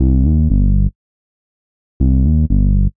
Under Cover (Bass) 120BPM.wav